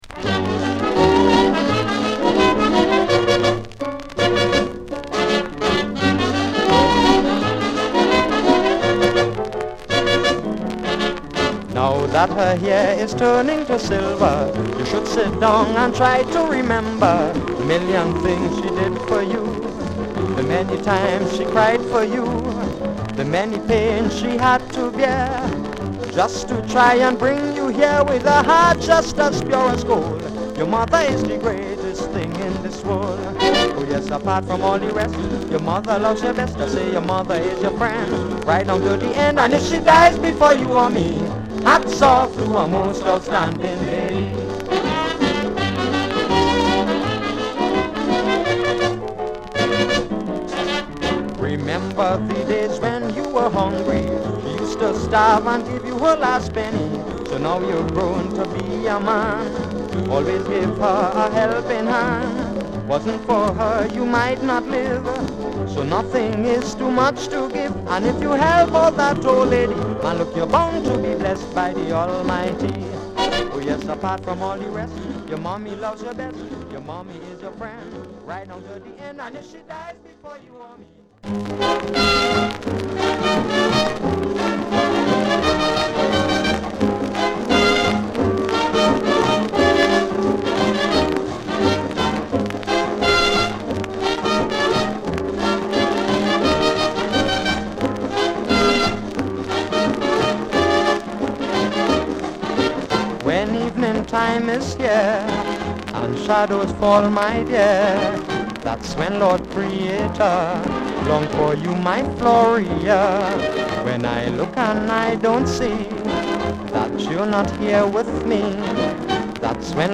Trinidad & Tobago
両面共に優しい男性ヴォーカルに小気味良いホーンをfeat.した期待を裏切らないカリプソチューン！